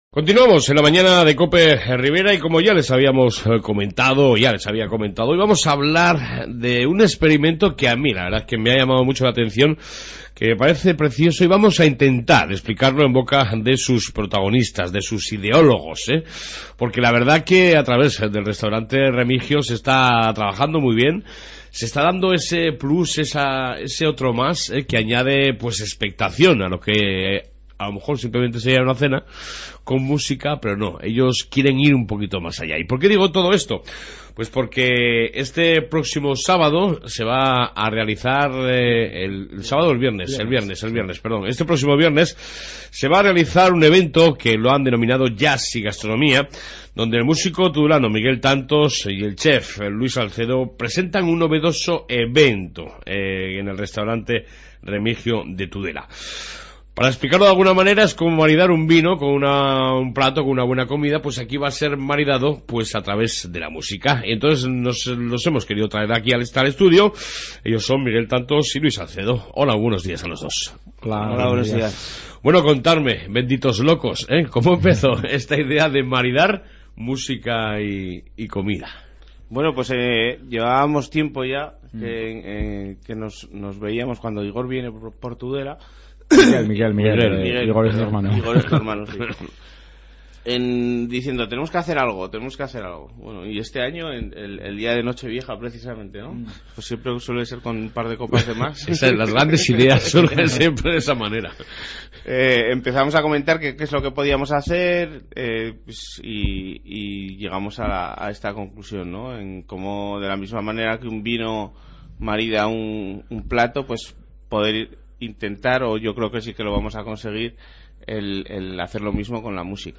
AUDIO: Que lujo y que placer entrevistar a 2 artistas Tudelanos unidos para crear algo diferente...Aquí esta el futuro!!Hoy hablamos de la...